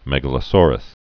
(mĕgə-lə-sôrəs)